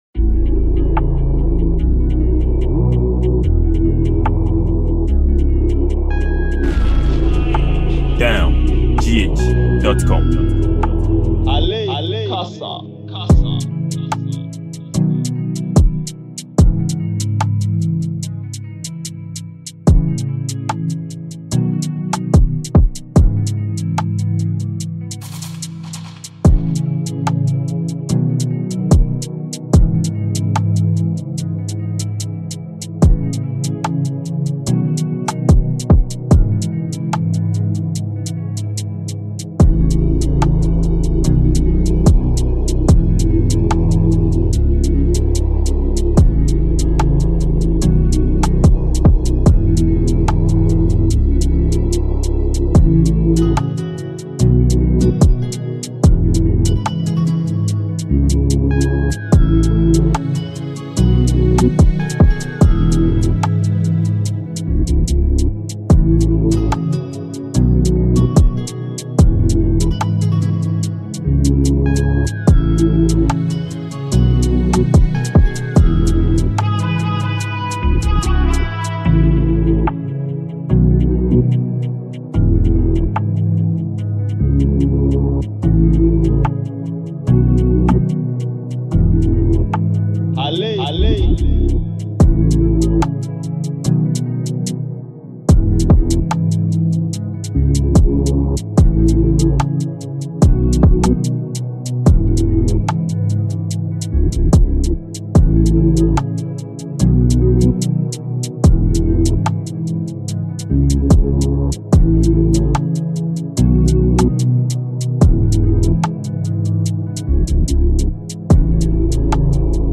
feel free to download the beat mp3 below.